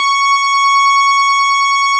snes_synth_073.wav